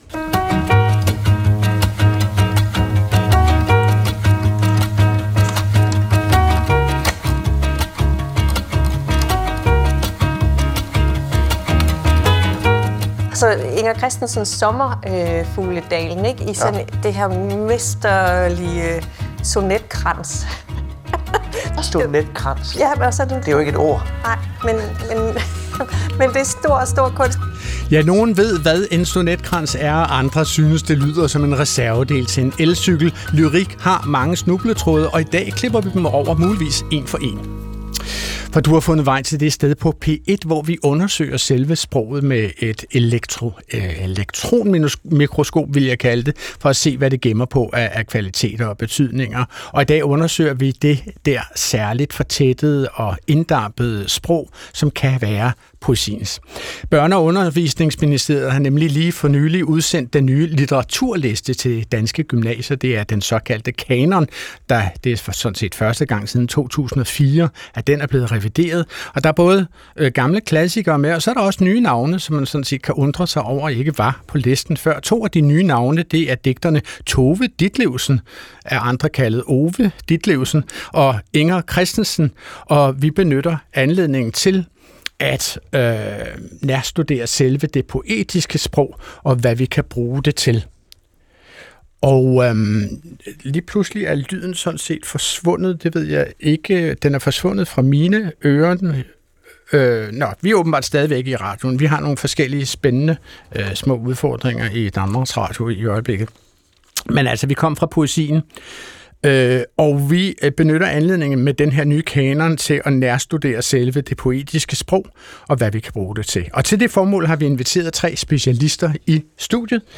Klog på Sprog er programmet, der interesserer sig for, leger med og endevender det sprog, vi alle sammen taler til daglig. Adrian Hughes er værten, der sammen med et veloplagt panel, er helt vild med dansk.